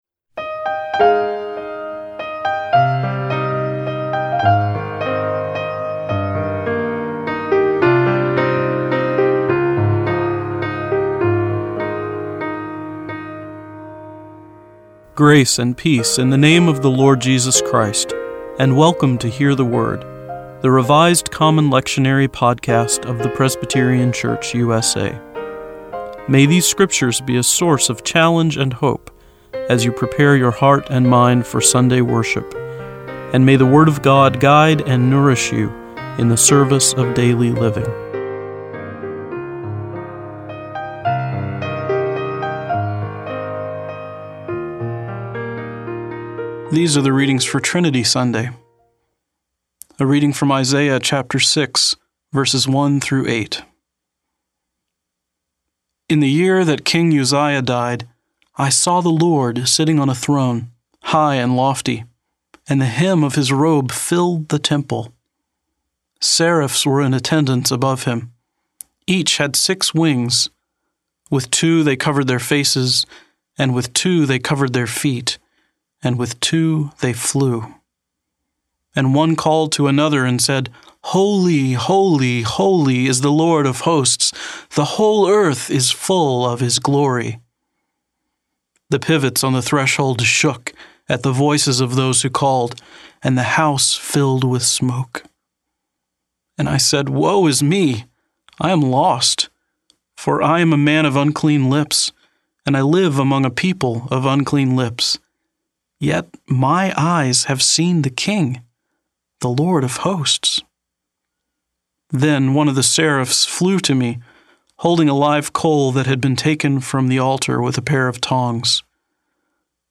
Each podcast (MP3 file) includes four lectionary readings for one of the Sundays or festivals of the church year: an Old Testament reading, a Psalm, an Epistle and a Gospel reading. Following each set of readings is a prayer for the day from the Book of Common Worship.